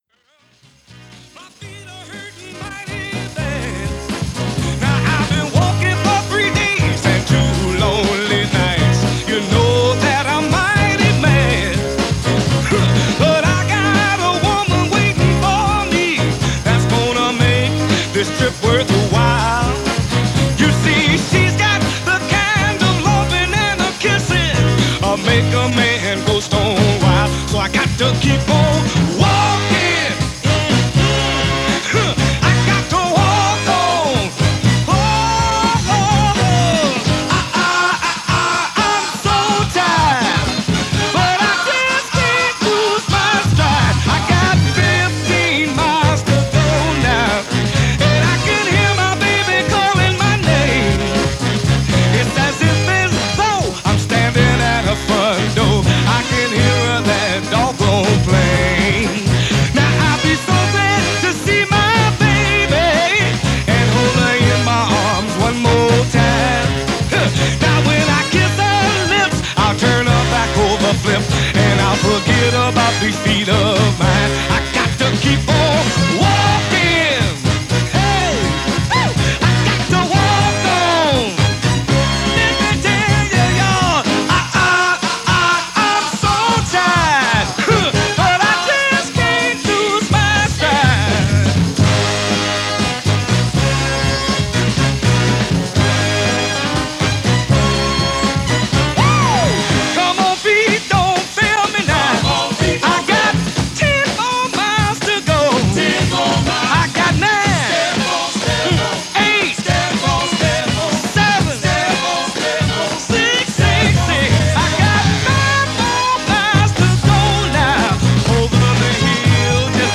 All that going on, but it was still Spring and WOR-FM was covering your town with music, just like it did on April 2, 1969.